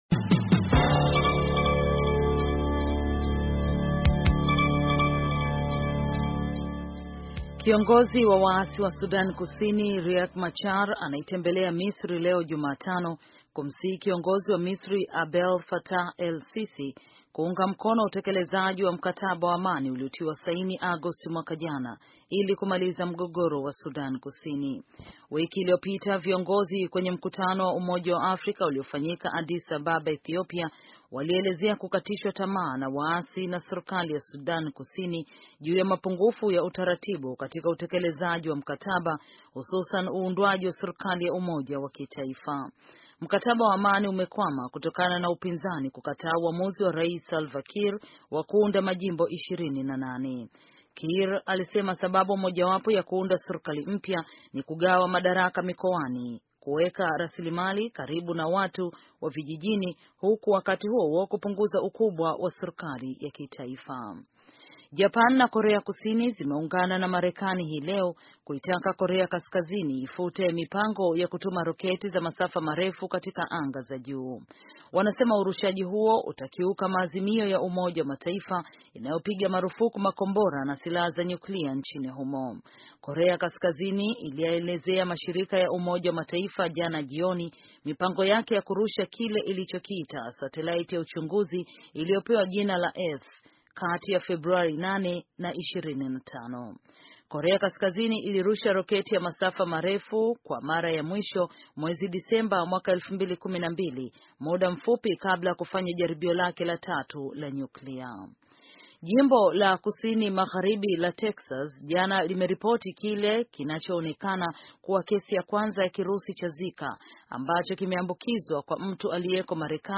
Taarifa ya habari - 5:22